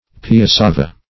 Search Result for " piassava" : The Collaborative International Dictionary of English v.0.48: Piassava \Pi*as"sa*va\, n. [Pg. piasaba.]